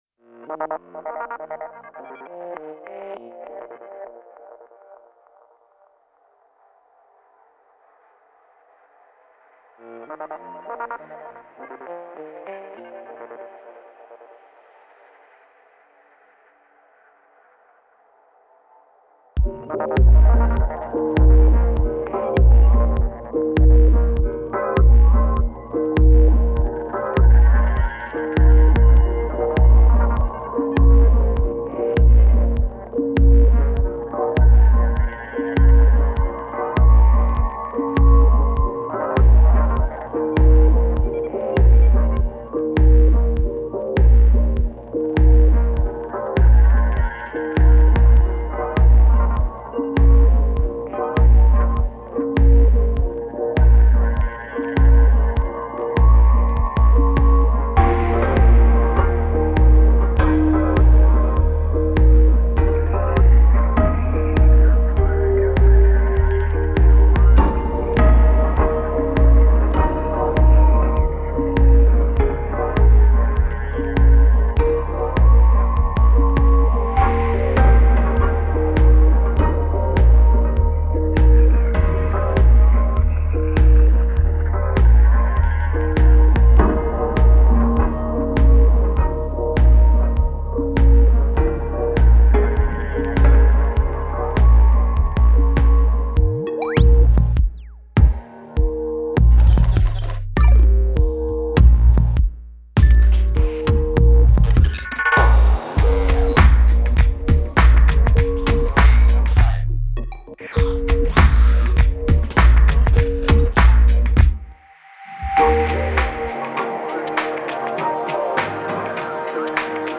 Genre: Downtempo, Chillout.